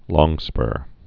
(lôngspûr, lŏng-)